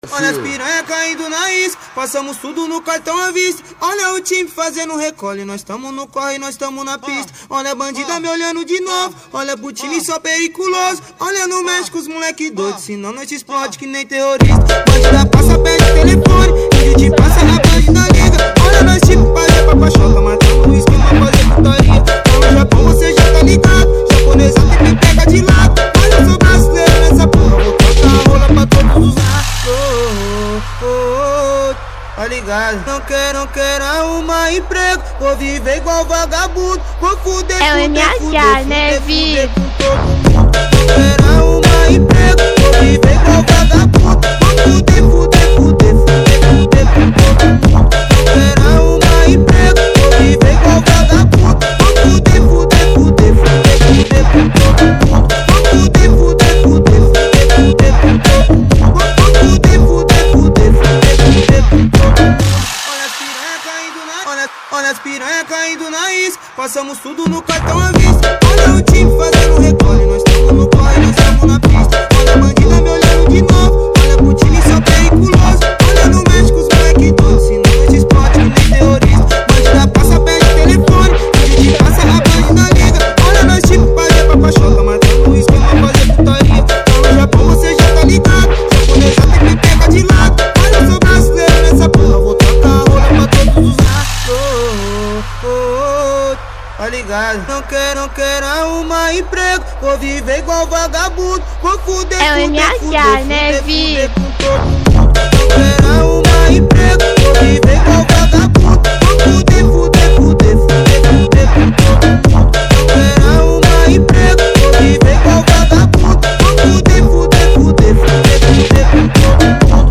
ELETROFUNK
2024-02-20 03:00:28 Gênero: Funk Views